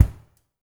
LG KICK1  -L.wav